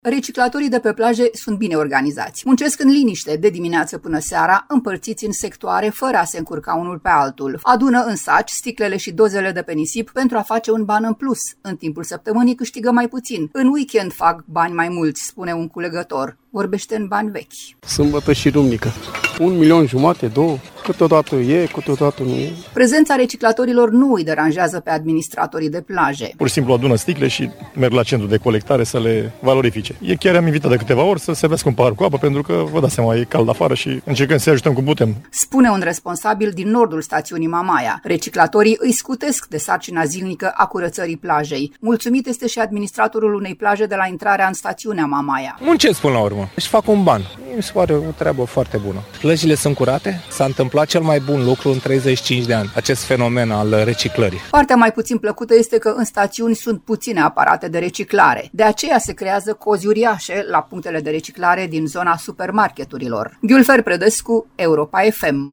În timpul săptămânii câștigă mai puțin, în weekend fac mai mulți bani spune un culegător.